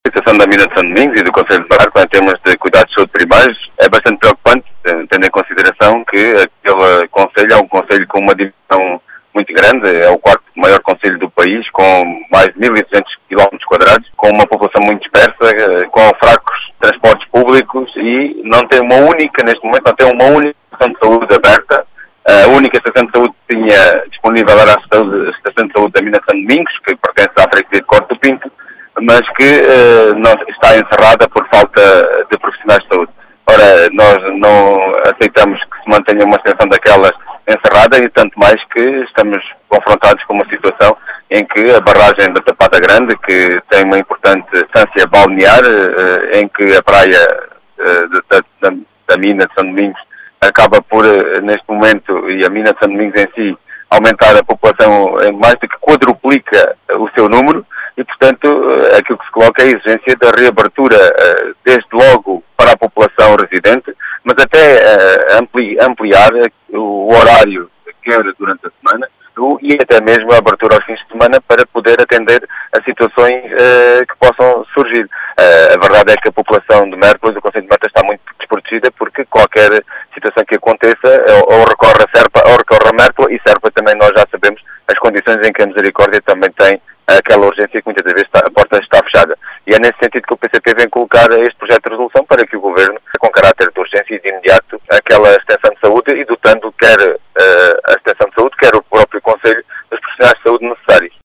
As explicações foram deixadas por João Dias, deputado do PCP eleito pelo distrito de Beja.